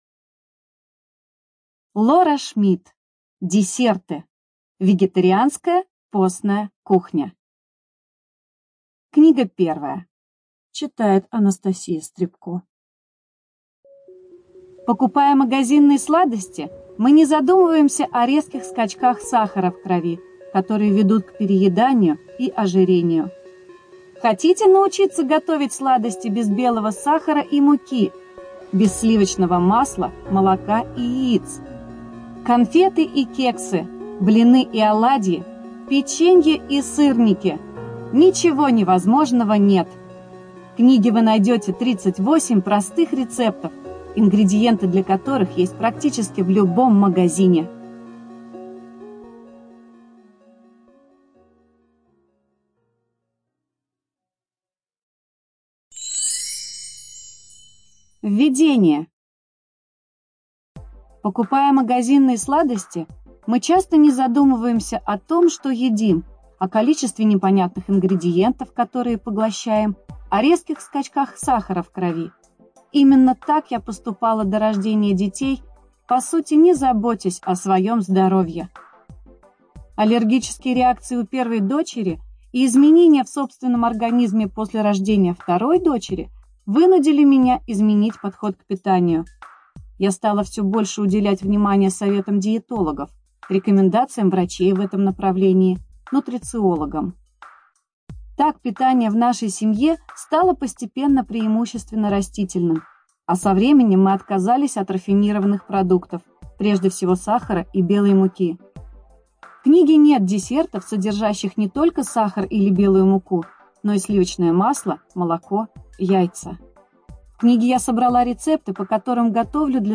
ЖанрКулинария